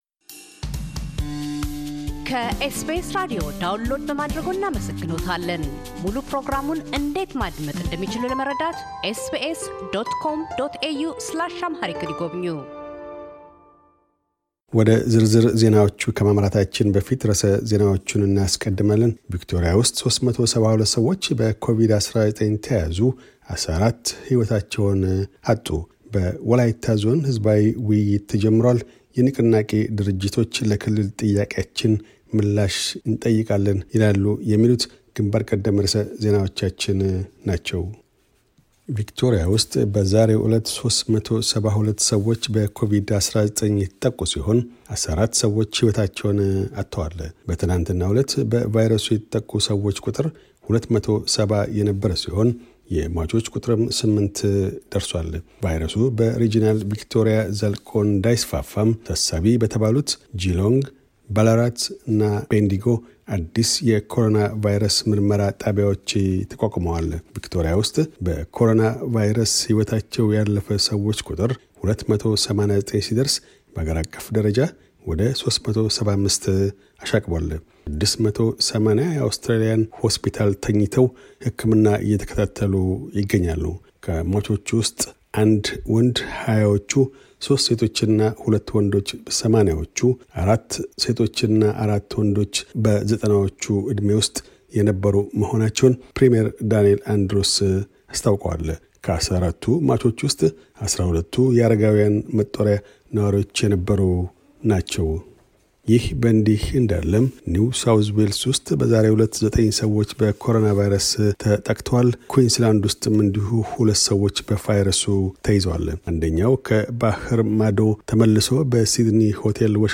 amh_news_podcast_1408.mp3